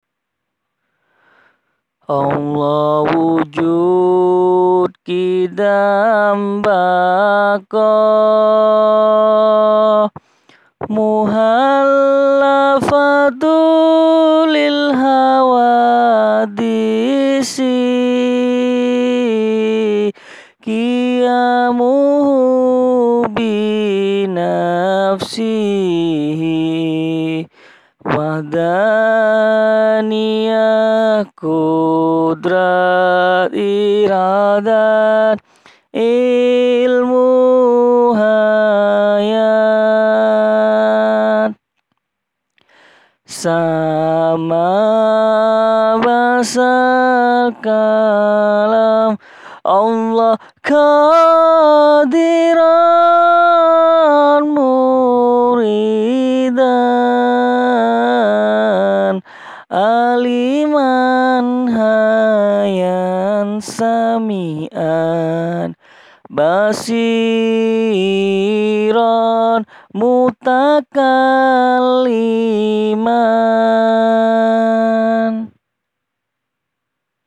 Maaf kalau suaranya jelek, ini asli saya rekam sendiri bacaan pujian yang biasanya saya pakai.
Mohon maaf sebelumnya jikalau suara saya jelek :).